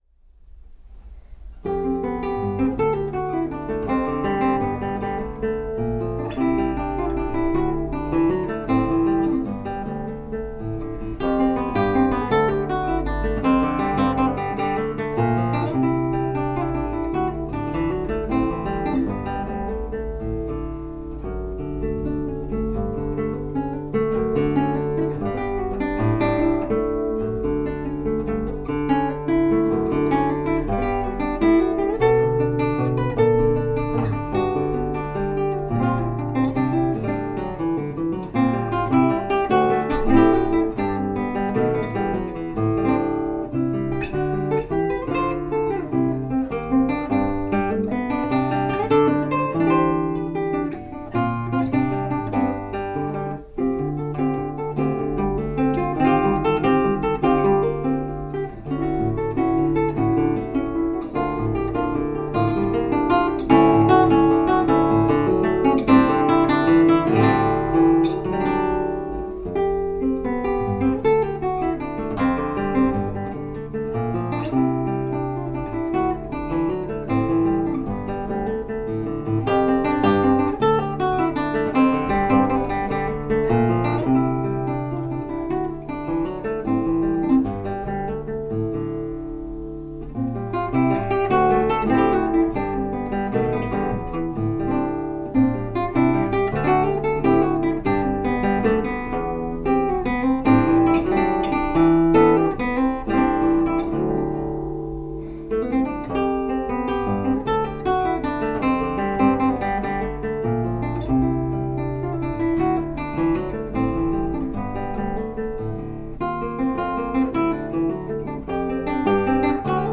Chitarra Classica
live